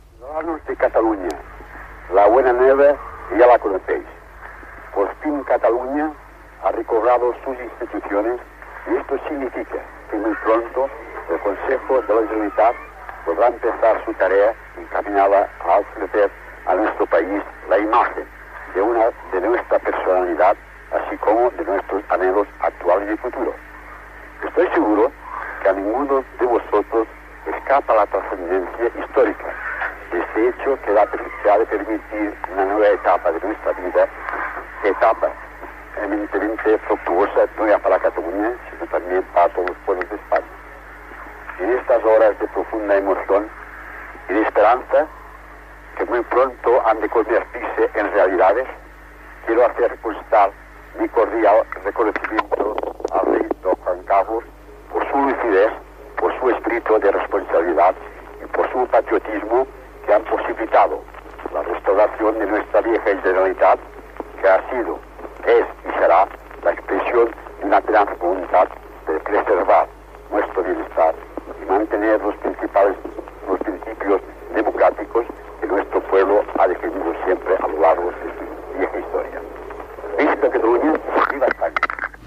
Paraules del president de la Generalitat de Catalunya Josep Tarradellas, des de França, dies abans de poder tornar a Catalunya i reinstaurar la Generalitat
Informatiu